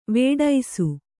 ♪ vēḍaisu